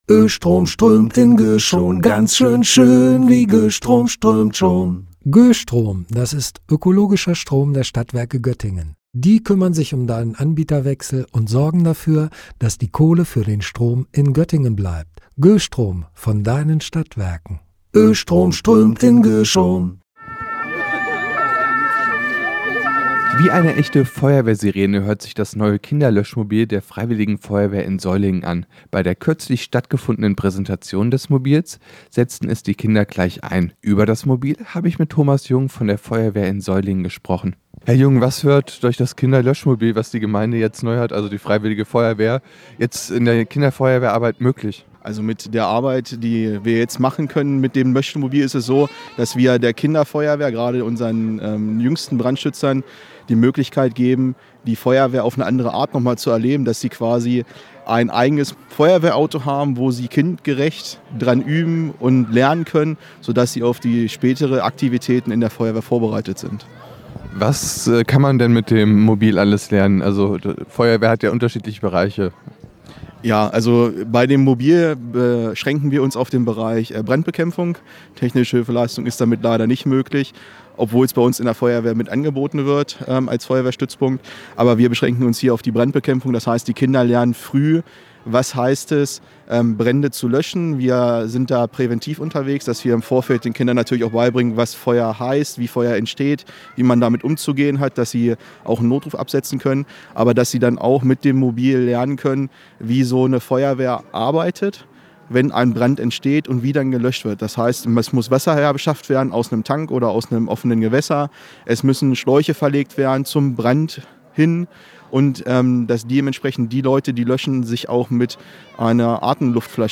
Die Freiwillige Feuerwehr in Seulingen investiert in die Jugendarbeit und hat ein Feuerwehrauto für Kinder angeschafft. Kürzlich hat die Feuerwehr es präsentiert.